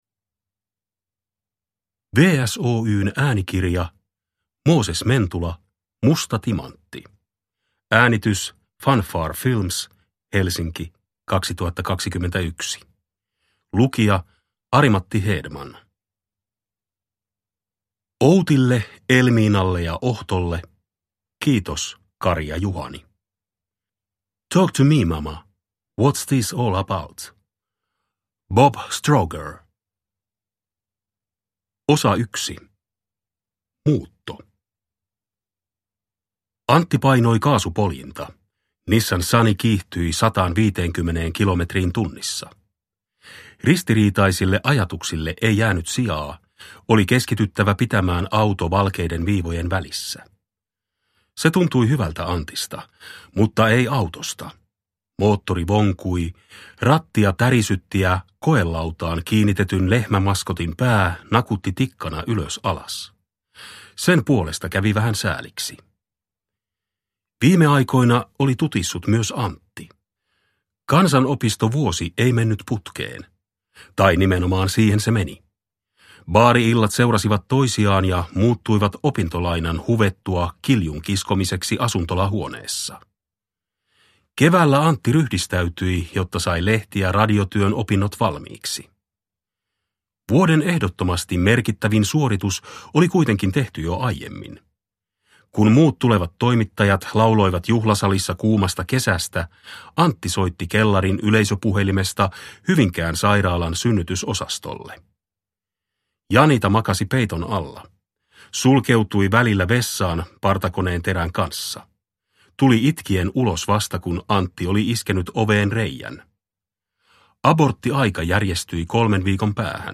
Musta timantti – Ljudbok